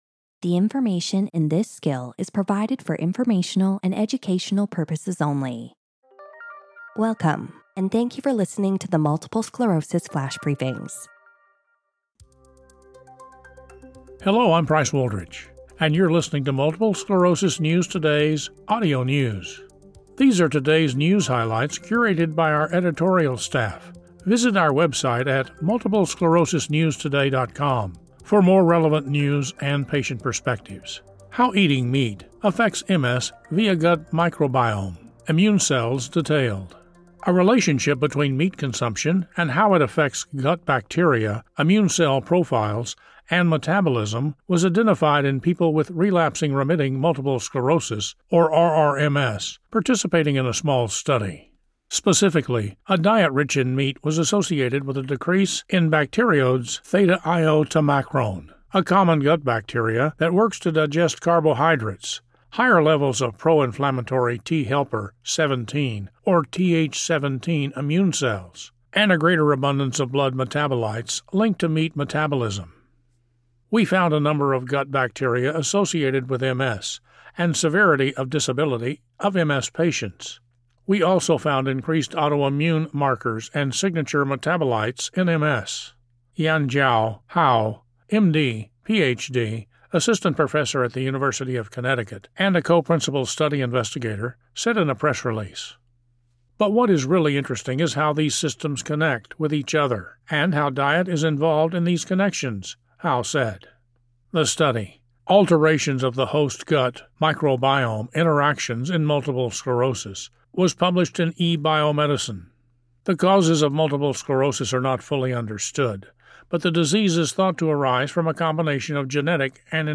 reads the news article about a study in 24 RRMS patients linked meat consumption with changes to the immune system, gut microbiome, and metabolism relative to controls.